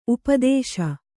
♪ upadēśa